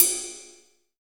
D2 RIDE-03-L.wav